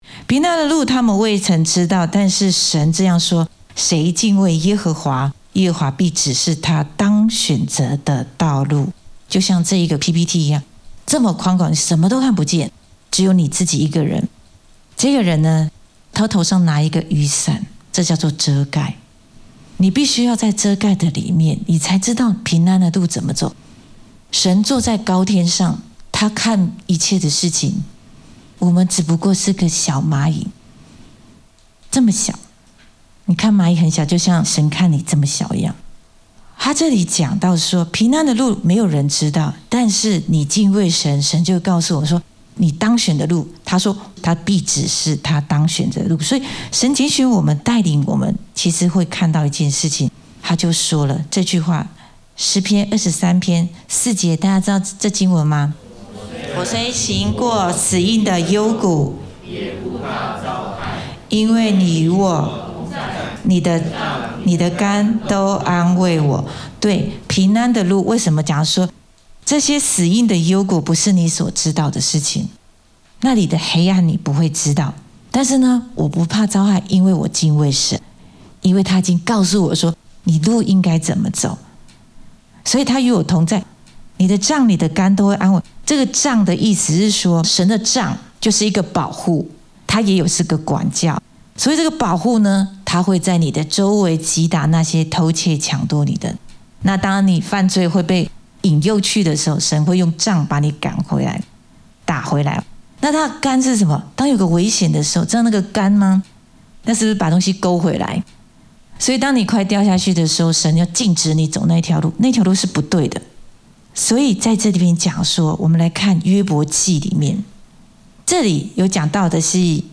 in 主日信息